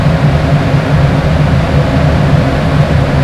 prius_low.wav